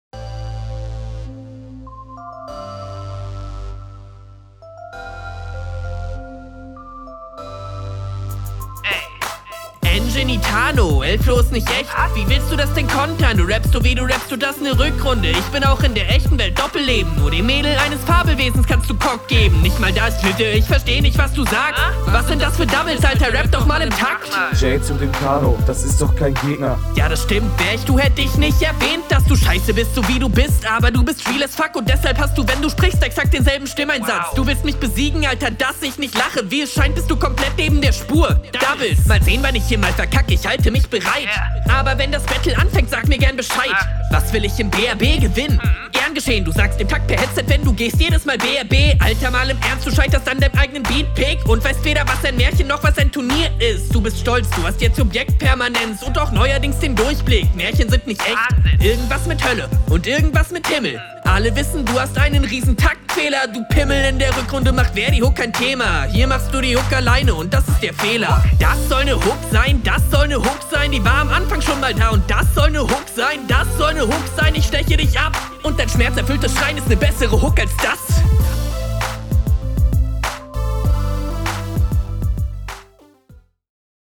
In Sachen Flow und Doubles kann sich dein Gegner eine Scheibe abschneiden.